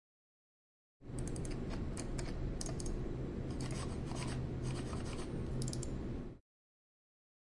电脑 " 鼠标电脑
描述：当有人点击并移动鼠标时，此声音会显示鼠标噪音。此外，还有与计算机风扇相对应的背景噪音。录制的声音要求有人使用Edirol R09HR设备移动并点击鼠标，格式为.wav和44.1 kHz。它被录制在Tallers建筑的计算机房中在UPF校园Poblenou。